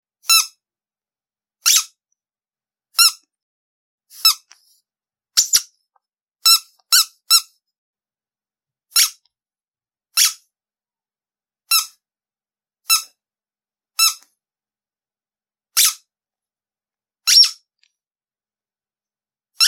Download Squeaky sound effect for free.
Squeaky